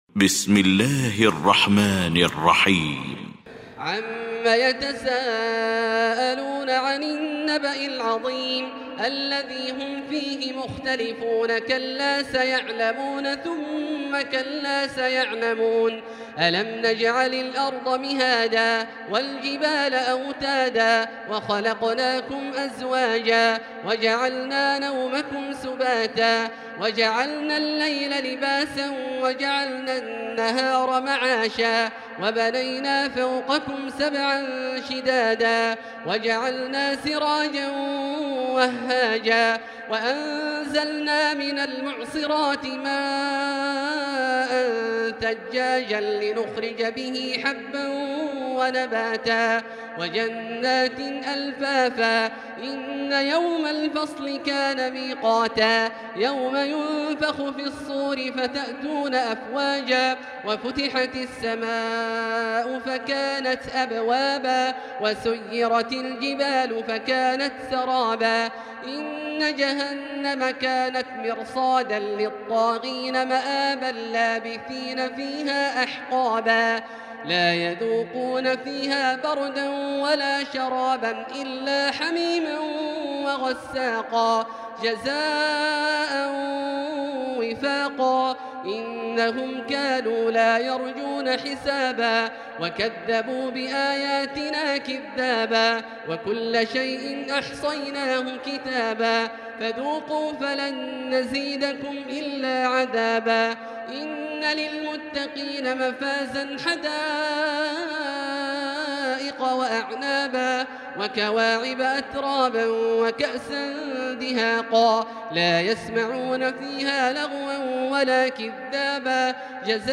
المكان: المسجد الحرام الشيخ: فضيلة الشيخ عبدالله الجهني فضيلة الشيخ عبدالله الجهني النبأ